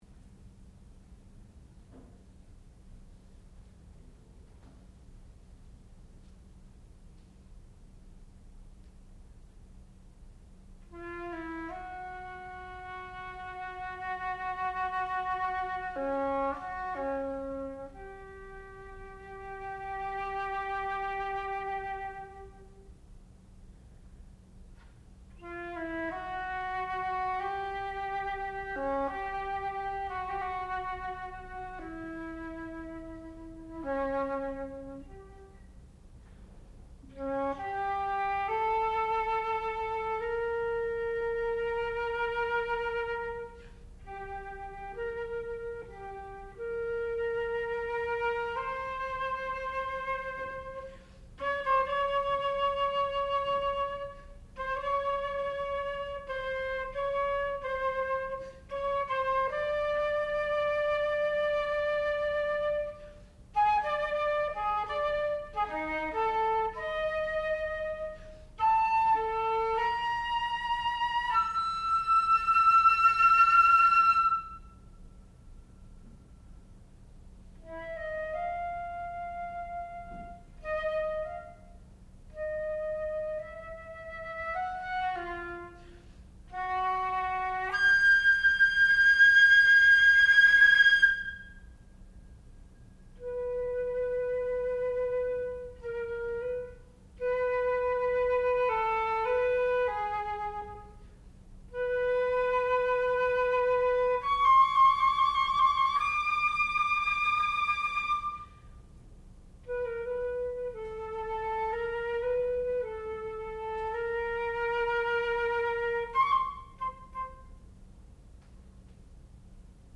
sound recording-musical
classical music
flute
Graduate recital